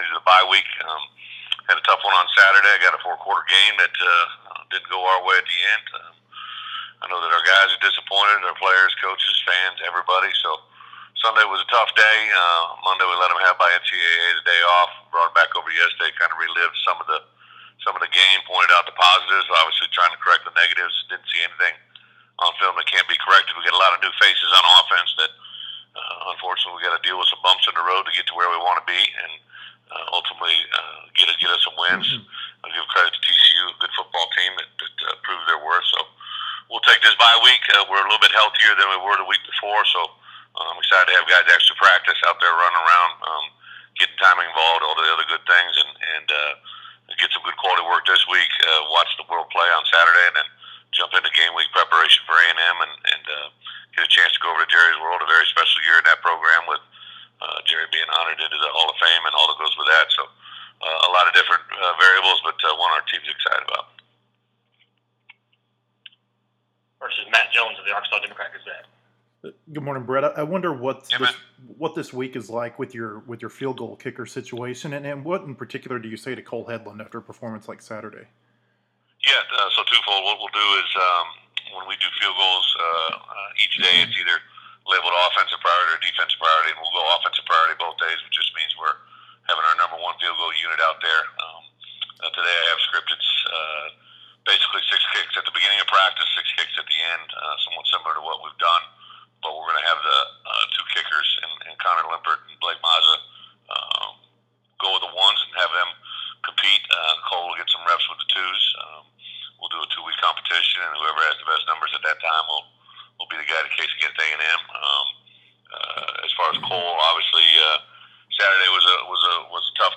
Bret Bielema's SEC teleconference on TCU loss, bye week